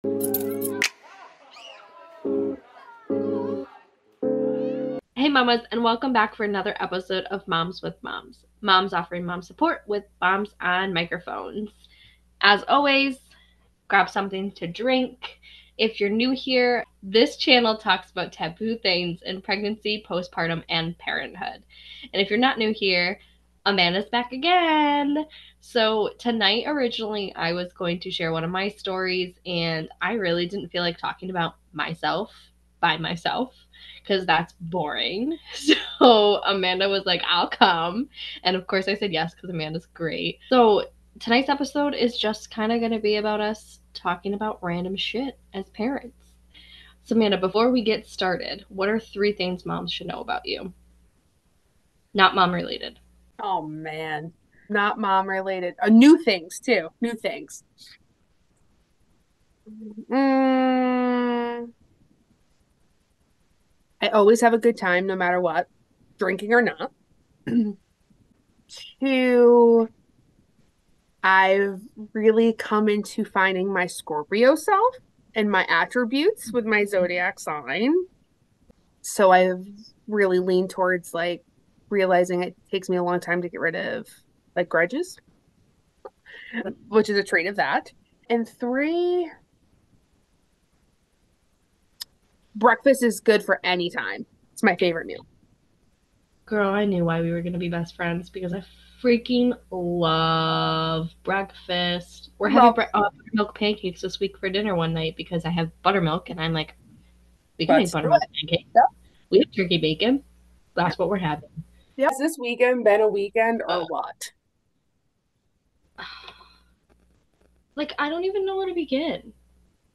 Where we have a bestie vent session talking about taboo things with pregnancy and parenthood from MOMS with MOMS.